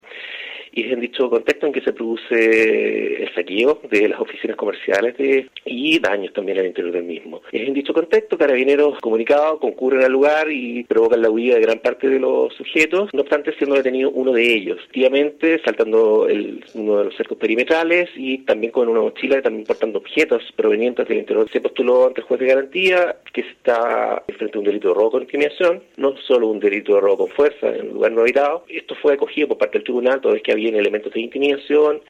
Asimismo, el persecutor señaló que hubo daños y saqueo en las oficinas. Estableciéndose además que los involucrados saltaron los cercos perimetrales.